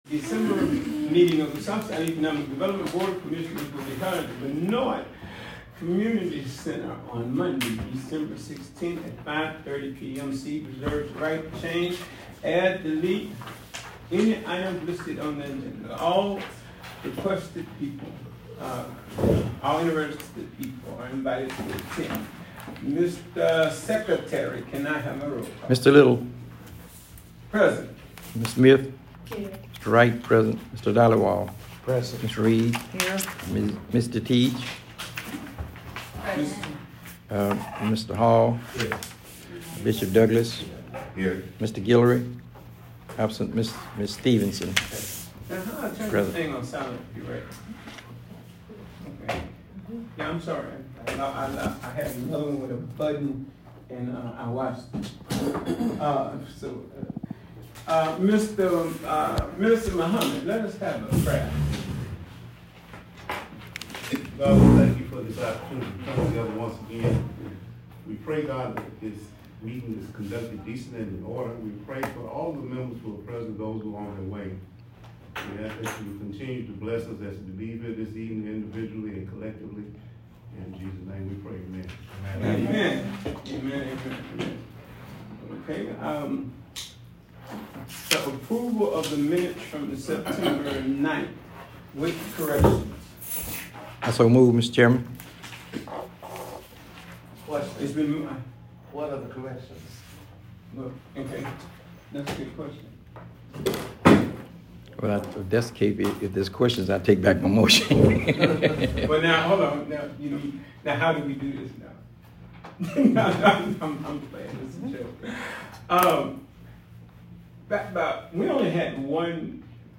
Draft of the minutes of the December 16, 2024 meeting of the SEDD held at the Benoit Recreation Center, subject to the approval of the board.